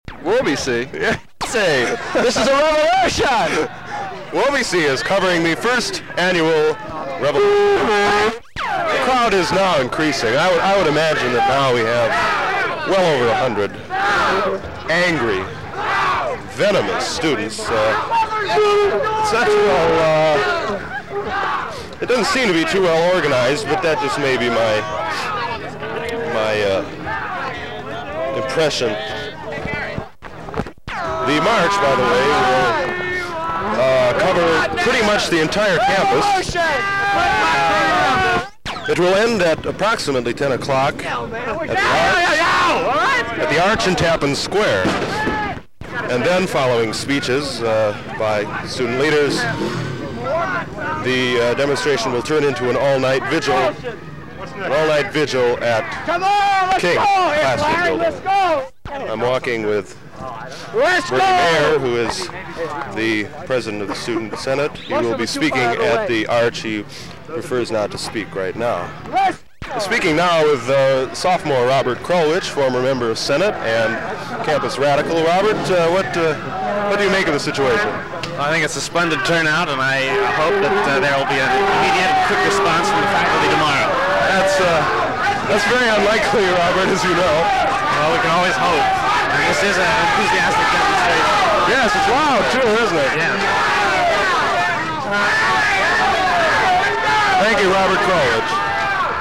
The noise here is deafening.
A chant went up at East Hall to urge the residents to come out and join the march:  "East out!  East out!"